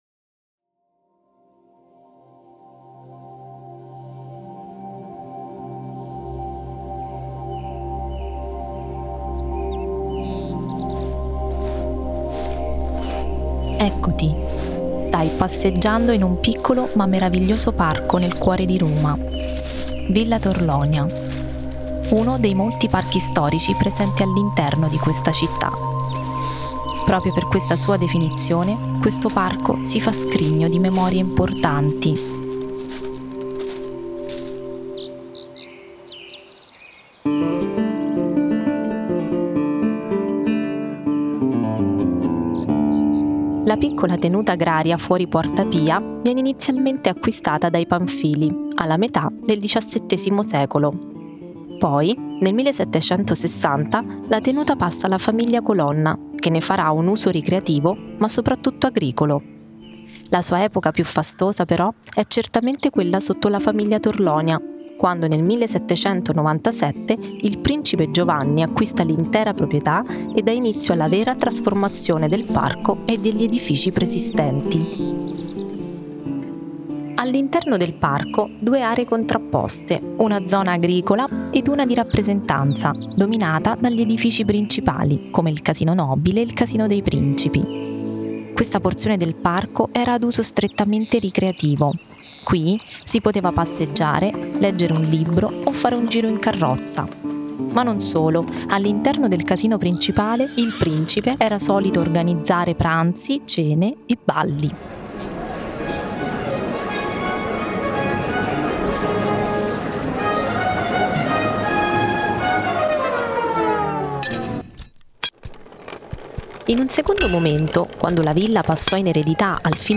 “Viaggio nel tempo a Villa Torlonia” una sonorizzazione immersiva di 9 minuti che ripercorre l’evoluzione storica del parco, attraverso aneddoti e storie legate agli edifici e ai suoi personaggi.